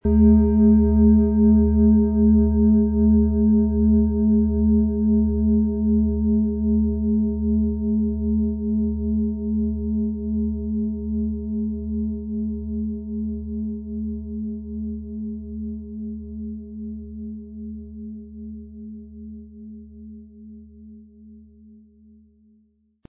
Planetenton 1
Unter dem Artikel-Bild finden Sie den Original-Klang dieser Schale im Audio-Player - Jetzt reinhören.
Lieferung mit richtigem Schlägel, er lässt die Klangschale harmonisch und wohltuend schwingen.
MaterialBronze